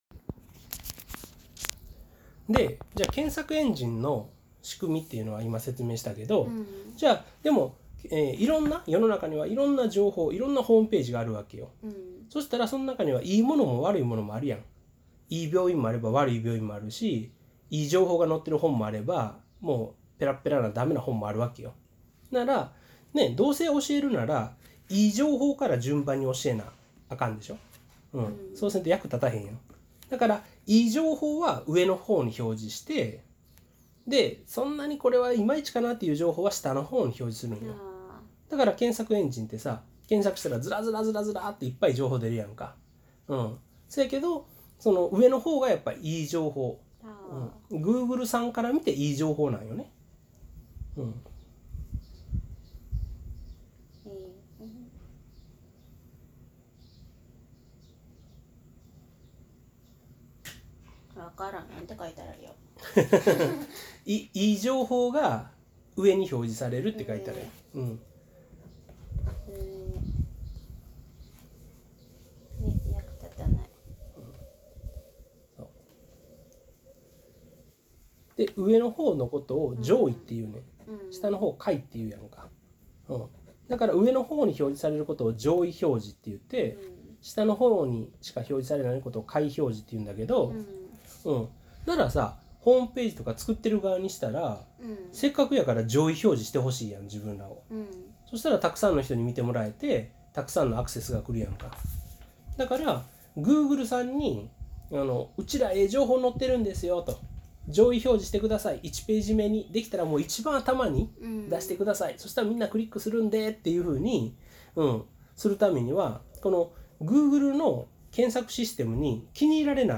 新しい試みとして、SEOを始めとしたインターネット全般に詳しくない高校卒業したての18歳女子に、検索エンジン（という言葉も知らなかった）とSEOについて説明してみました。
新しい「概念」を教えることは本当に難しく、私も苦戦している様子が感じられると思います（笑）